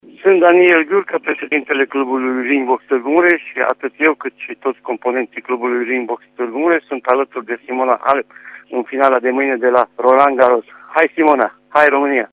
O dovedesc mesajele oamenilor de sport din județele Mureș și Harghita: